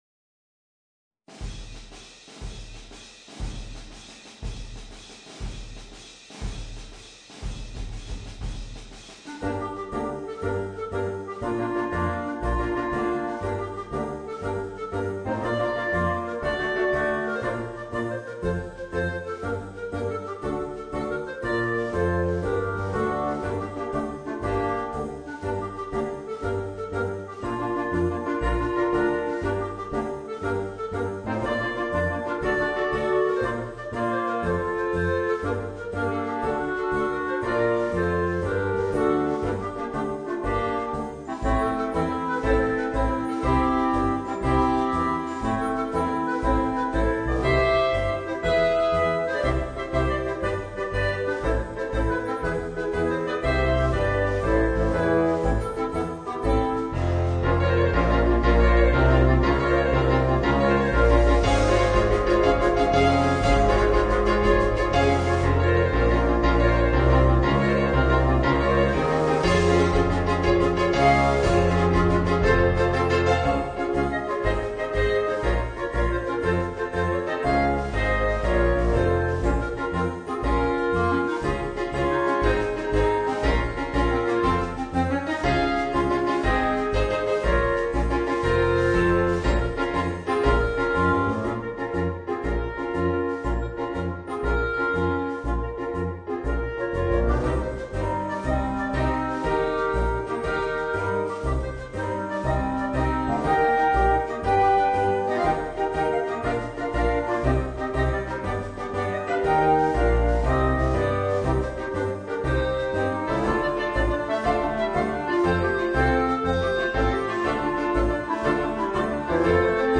Voicing: Woodwind Quartet and Rhythm Section